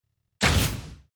x1_battle_chengjishihan_attack.mp3